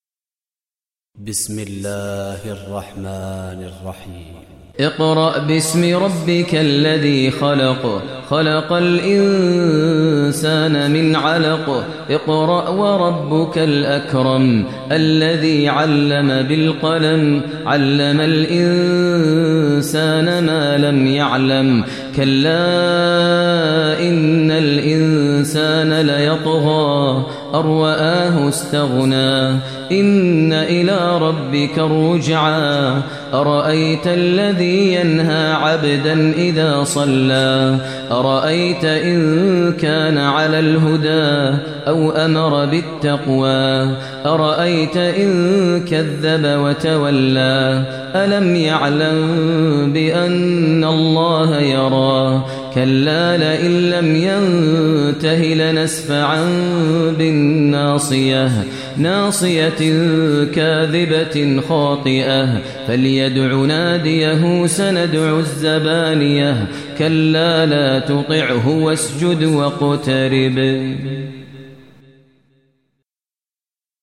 Surah Alaq Recitation by Maher al Mueaqly
Surah Alaq, listen online mp3 tilawat / recitation in Arabic in the voice of Imam e Kaaba Sheikh Maher al Mueaqly.